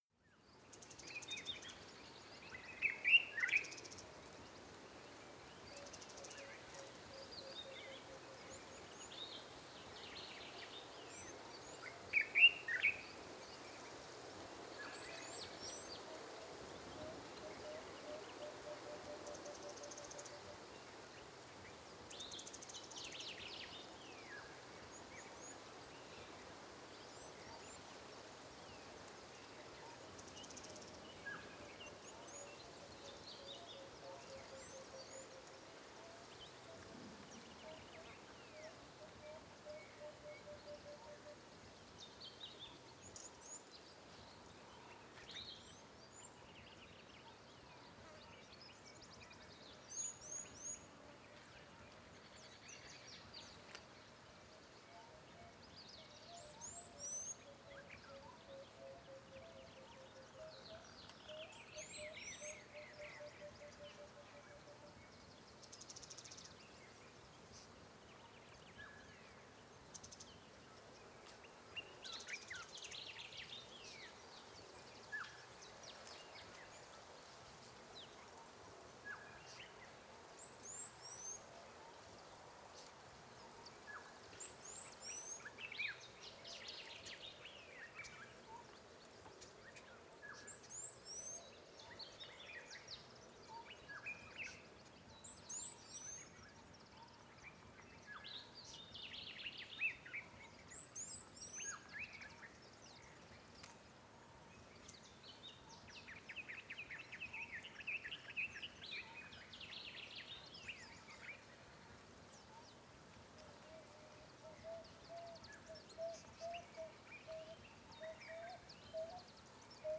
Gemafreie Sounds: Dschungel/Regenwald